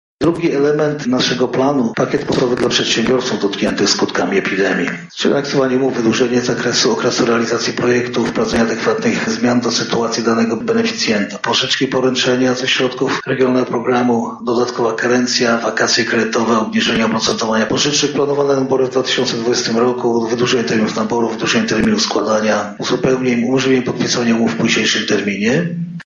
Pomoc placówkom medycznym to nie wszystko co mamy opracowane w Lubelskim Pakiecie Antykryzysowym – mówi Jarosław Stawiarski, Marszałek Województwa Lubelskiego.